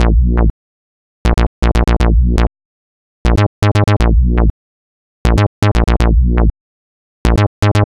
Bp Bass.wav